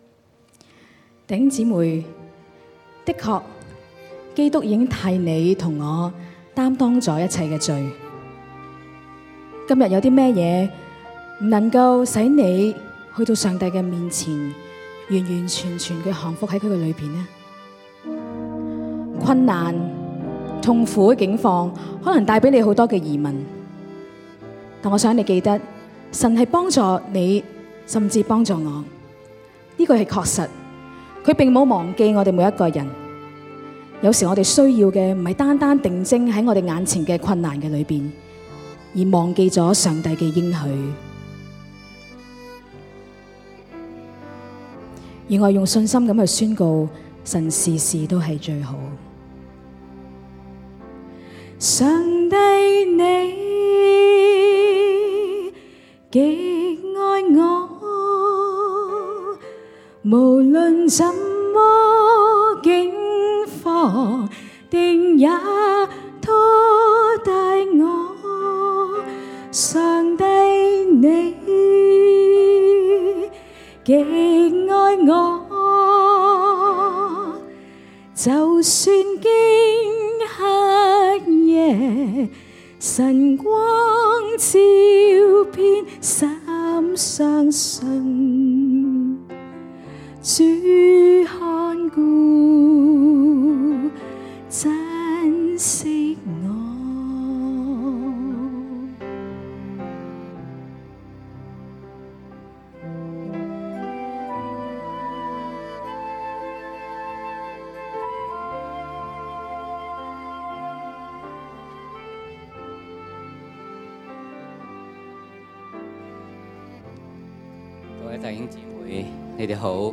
浸會大學基督教週培靈會
我們的敬拜
組曲
見證分享